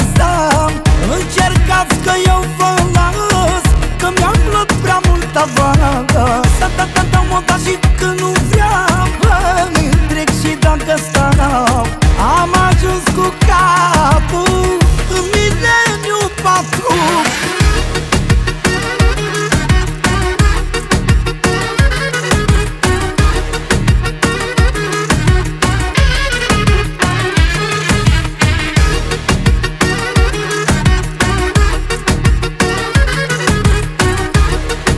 Жанр: Музыка мира